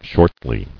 [short·ly]